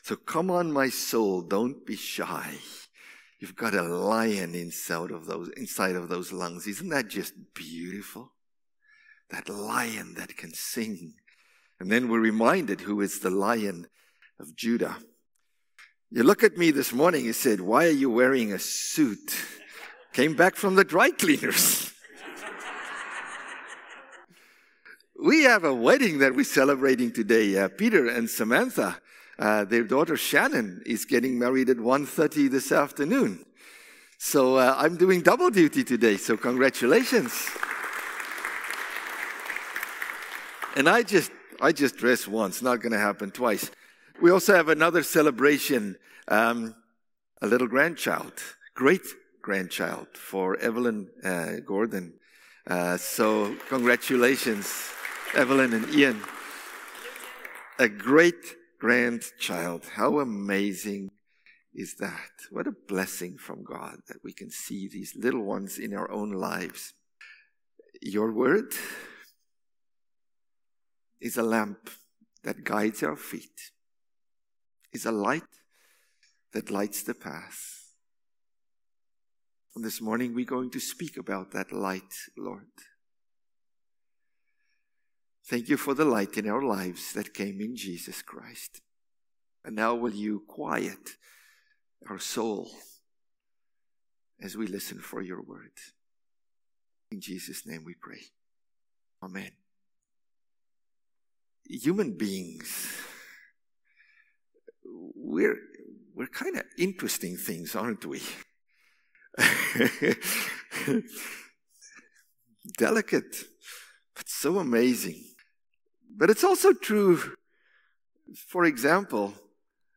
September-29-Sermon.mp3